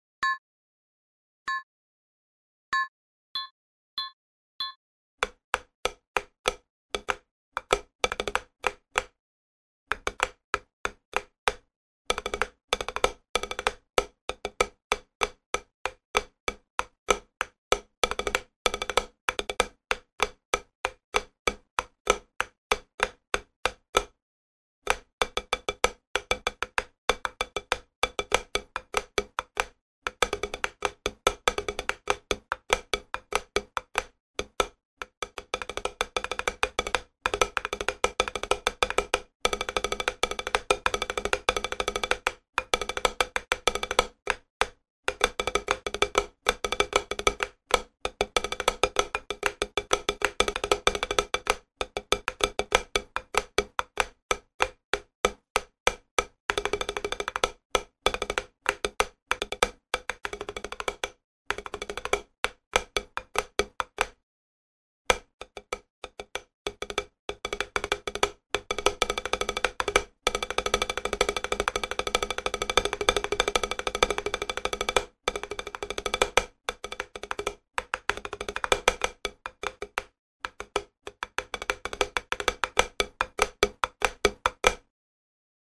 Рудиментальный этюд
Этюд №42 - в основе рудимент "Флэм-акцент №1"
Размер 6/8, темп - 96 bpm